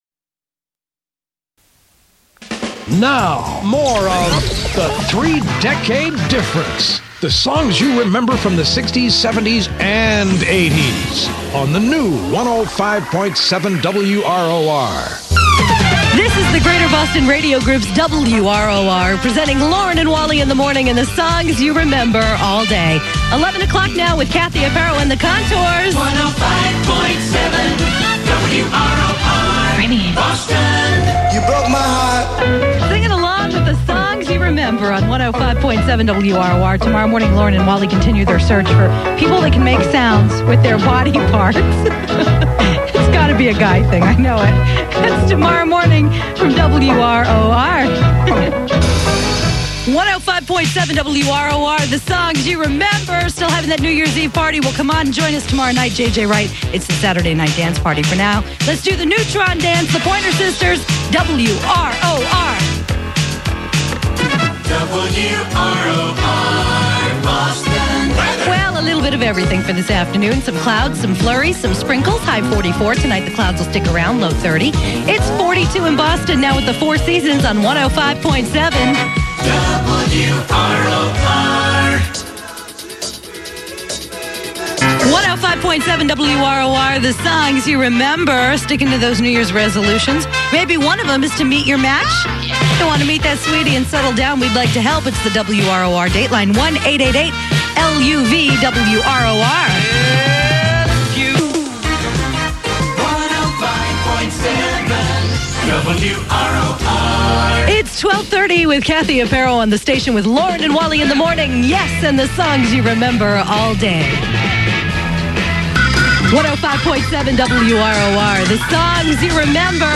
Voice Track Demos
(Aircheck)
(60's, 70's, & 80's)